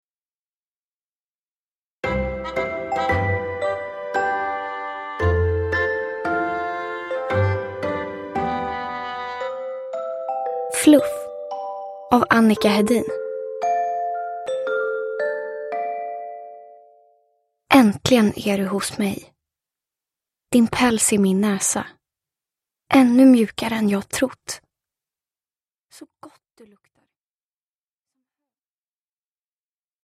Fluff – Ljudbok